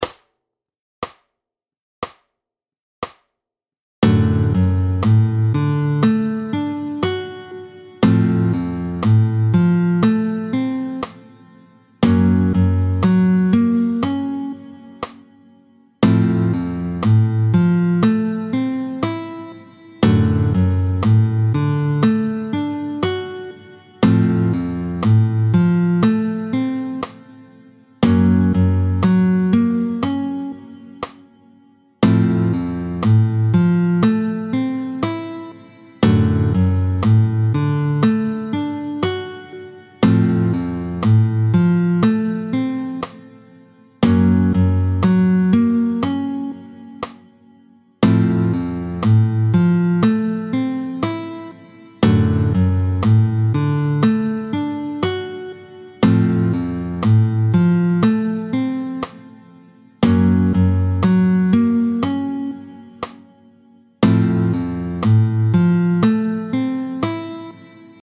29-02 Les barrés du pouce, tempo 60